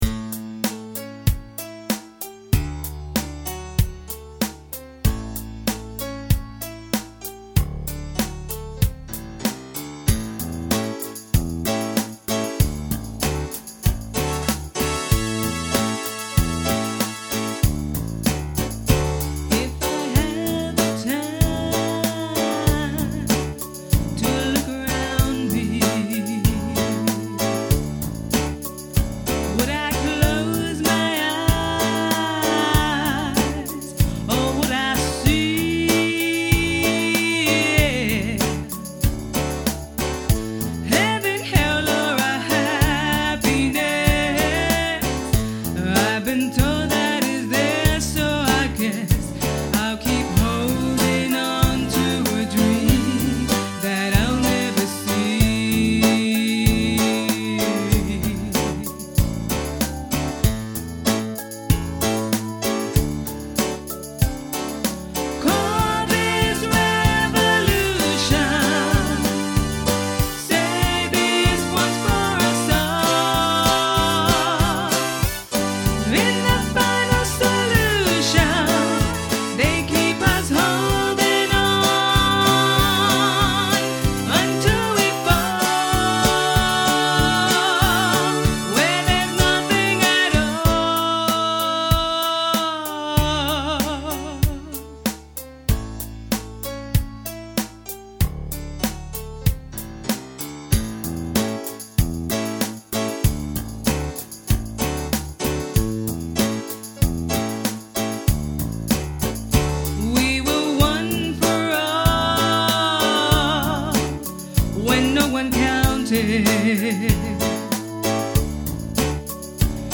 • (D) Sang Lead Vocals
• (E) Sang Backing Vocals
• (F) Played Drums
• (H) Played Keyboards